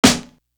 Marine Corps Snare.wav